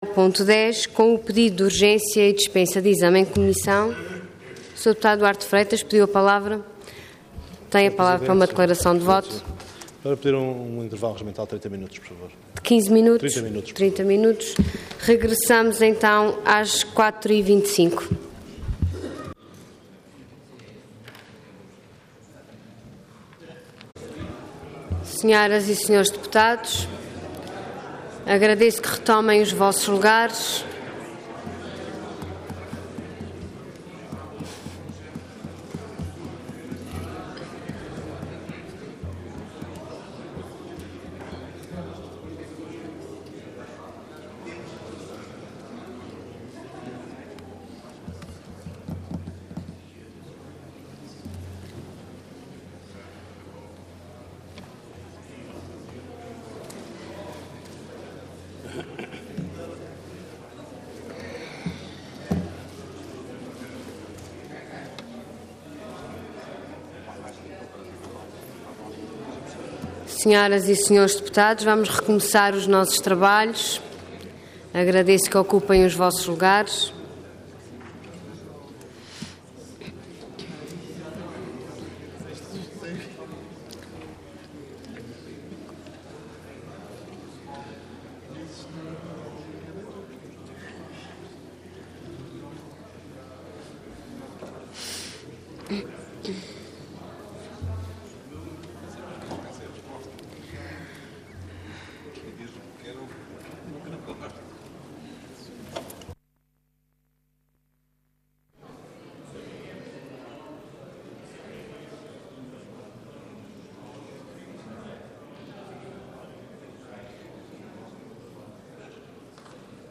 Assembleia Legislativa da Região Autónoma dos Açores
Intervenção
Pedido de urgência seguido de debate
Aníbal Pires
Deputado